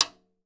08 TIMBALE S.wav